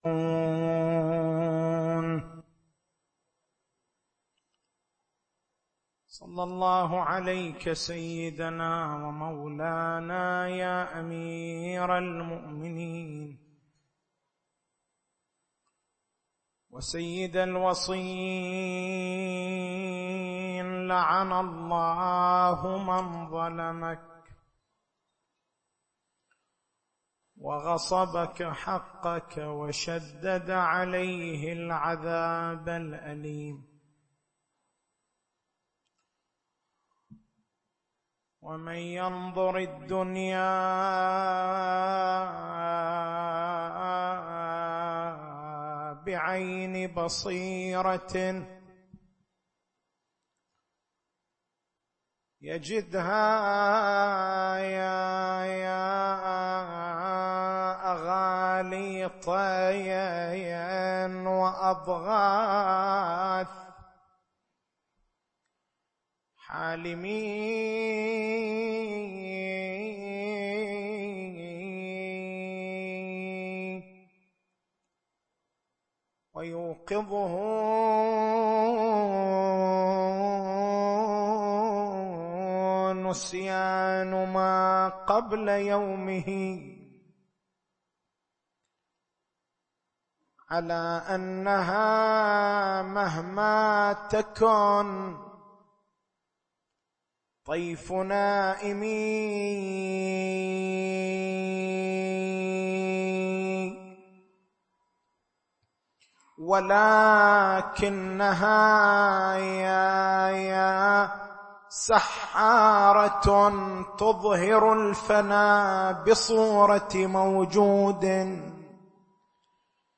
تاريخ المحاضرة: 22/09/1432 محور البحث: ثلاثة استدراكات على بحوث آية الولاية.